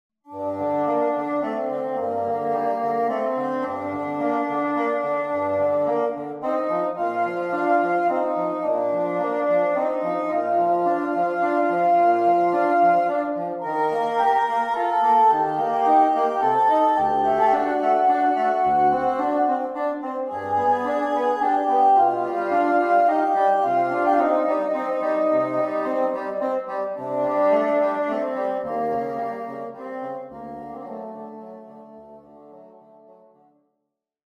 bassoon duet